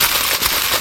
Shaker 09.wav